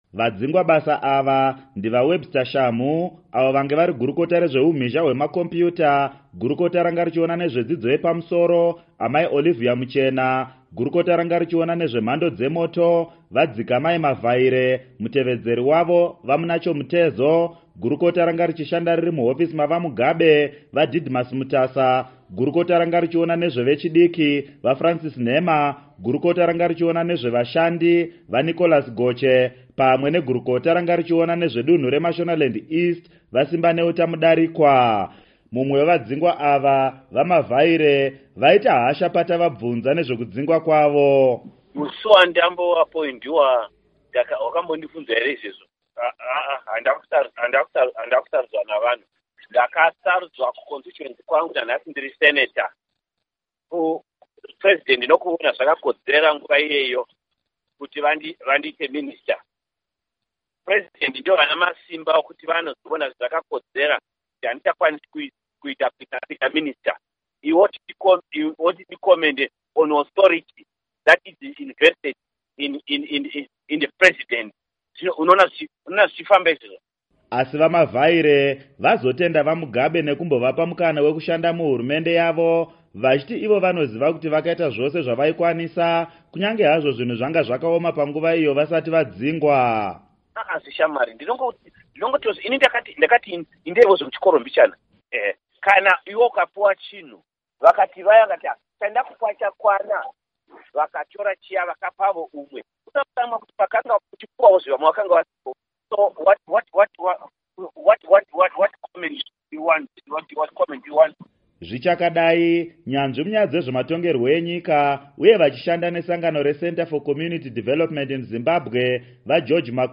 Hurukuro naAmai Joyce Mujuru